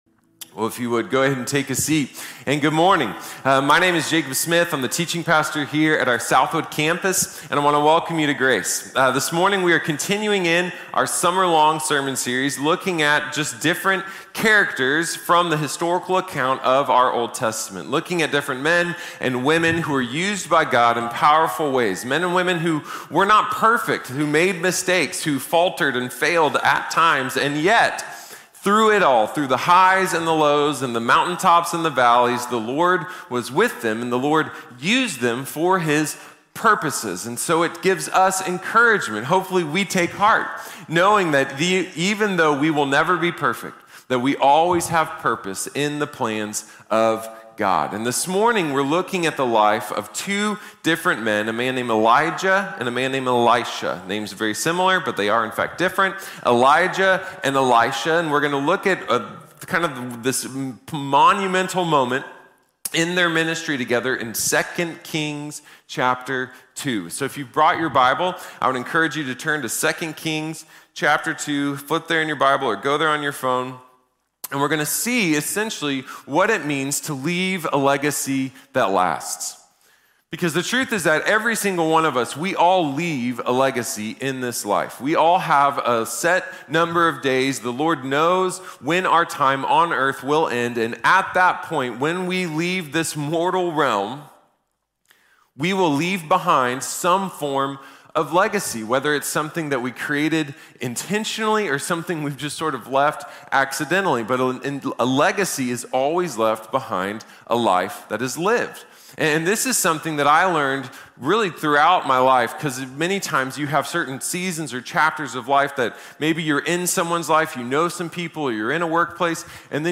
Elisha | Sermon | Grace Bible Church